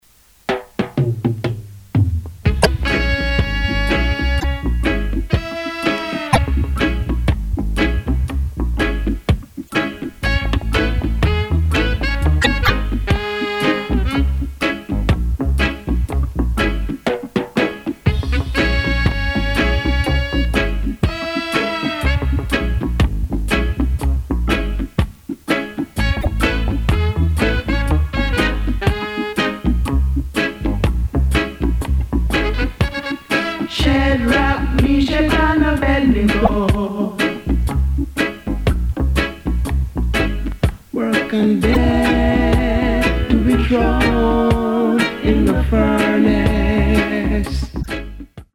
Recorded: Joe Gibbs 'N' Harry J. Studios Kgn. Ja.